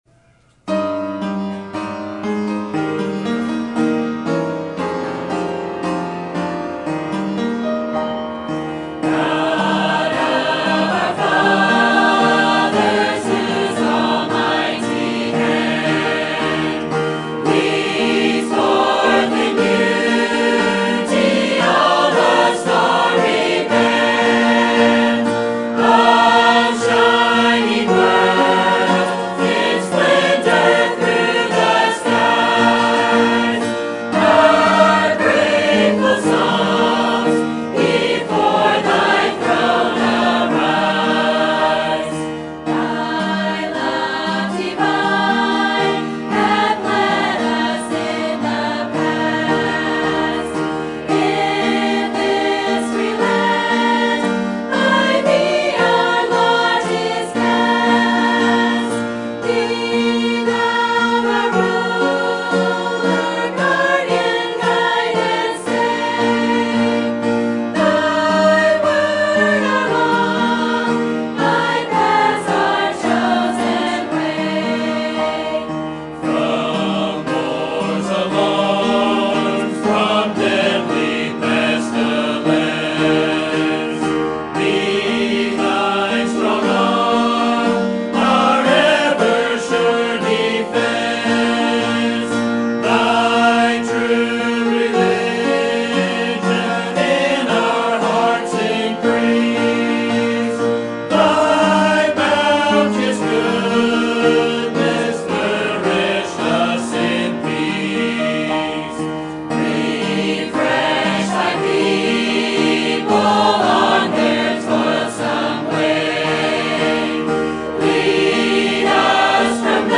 Sermon Topic: General Sermon Type: Service Sermon Audio: Sermon download: Download (28.75 MB) Sermon Tags: 1 Corinthians Freedom Thankful Blessing